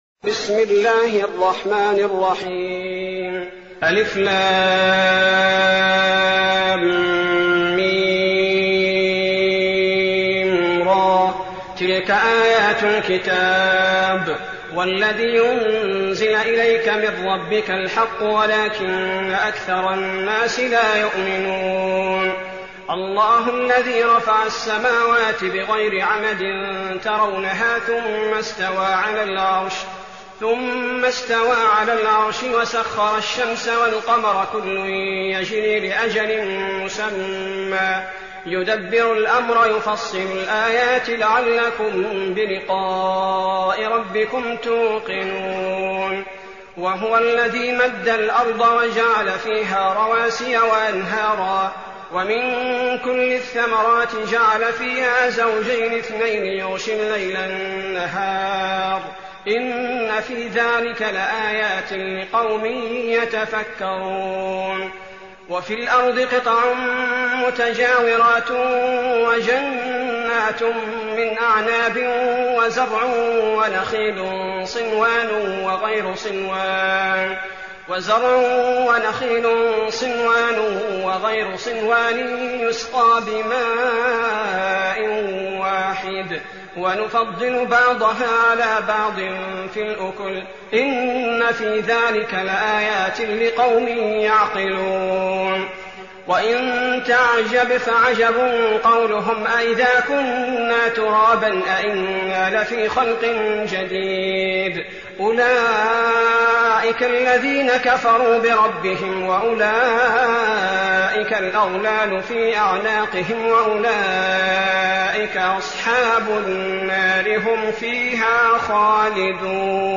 تراويح الليلة الثالثة عشر رمضان 1419هـ سورتي الرعد و إبراهيم (1-12) Taraweeh 13th night Ramadan 1419H from Surah Ar-Ra'd and Ibrahim > تراويح الحرم النبوي عام 1419 🕌 > التراويح - تلاوات الحرمين